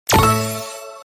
Win_Frame_Sound.MP3